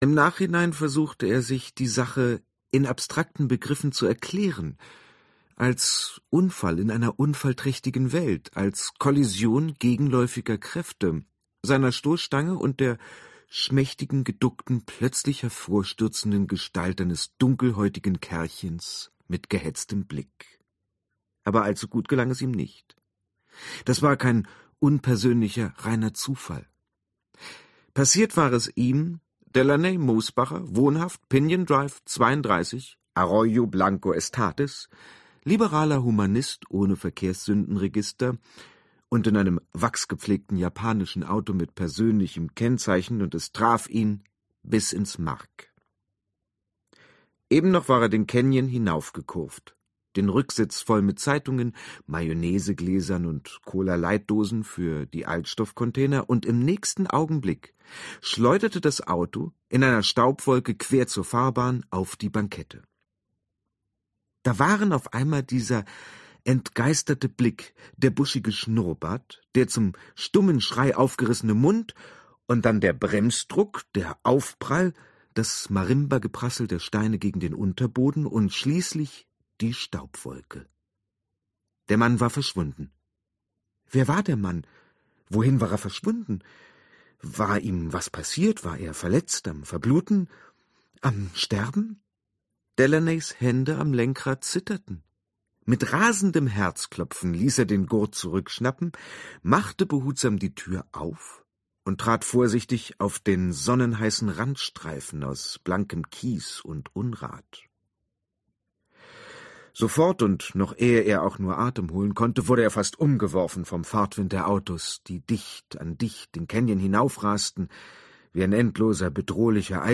Ausgabe: Gekürzte Lesung
Sprecher*innen: Boris Aljinovic